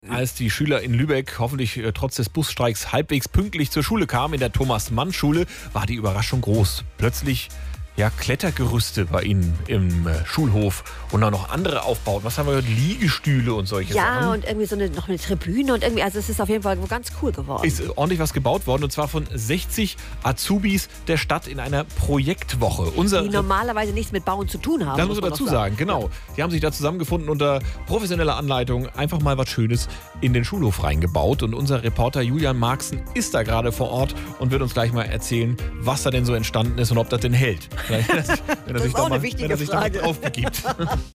NDR1-Welle-Nord-Live-Schalte-Azubis-bauen-Kletterparcours-24.10.2022Herunterladen